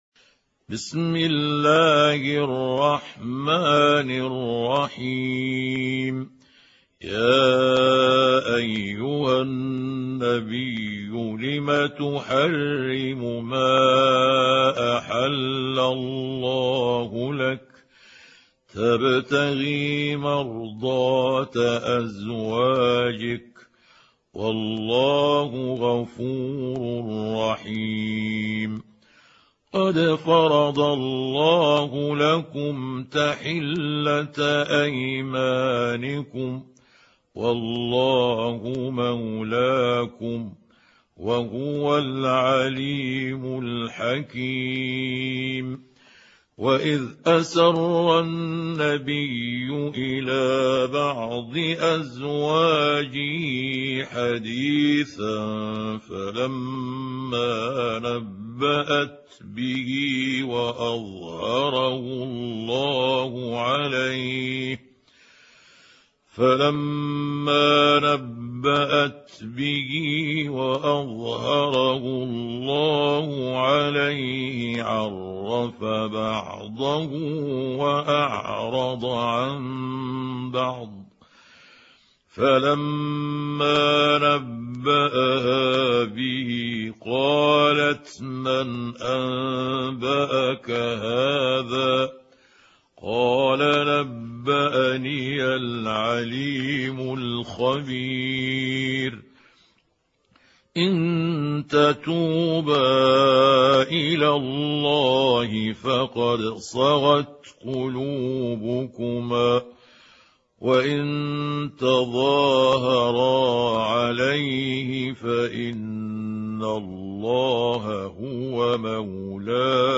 سورة التحريم | القارئ محمود عبد الحكم